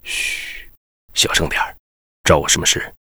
文件 文件历史 文件用途 全域文件用途 Bk2_tk_02.ogg （Ogg Vorbis声音文件，长度3.0秒，85 kbps，文件大小：31 KB） 源地址:游戏语音 文件历史 点击某个日期/时间查看对应时刻的文件。